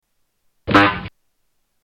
Shorter blast of Harpo's horn